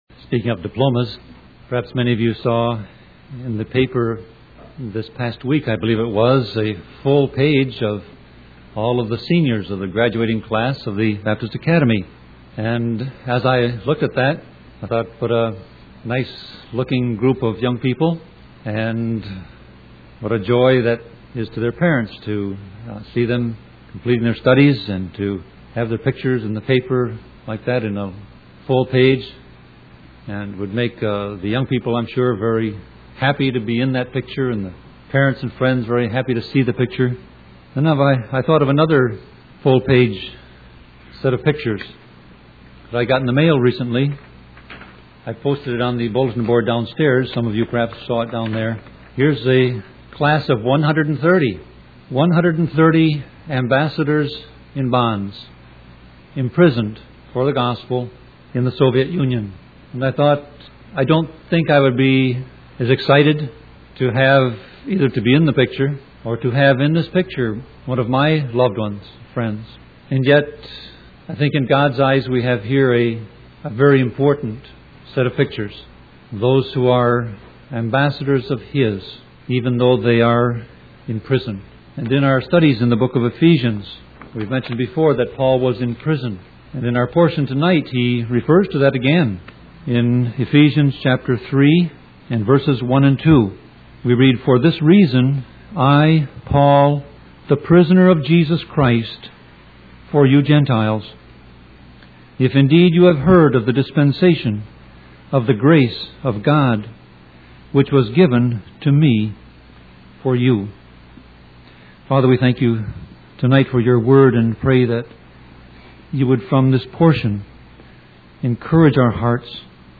Sermon Audio Passage: Ephesians 3:1-2 Service Type